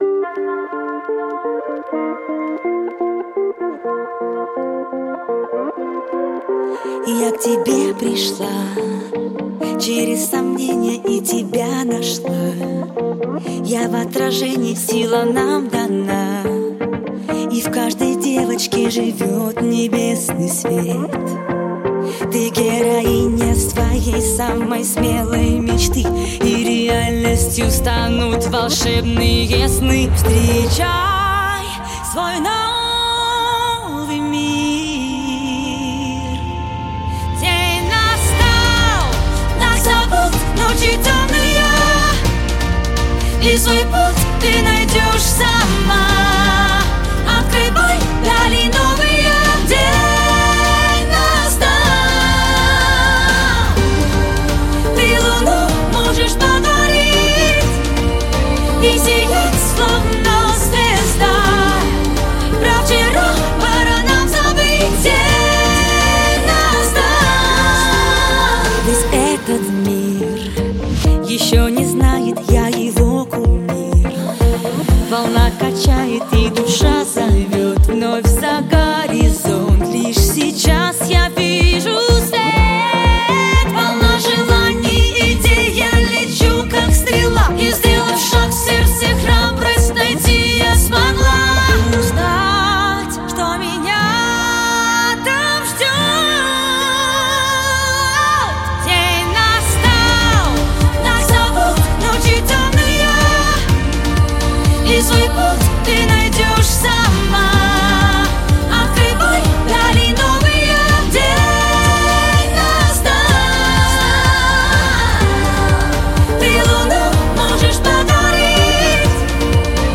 Русский поп